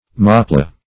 Moplah \Mop"lah\, n.